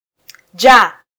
English: Pronunciation of Swahili "ja", spoken by a woman from Kenya.
Deutsch: Aussprache von Swahili "ja", gesprochen von einer Frau aus Kenia.
Pronunciation of Swahili "ja", spoken by a woman from Kenya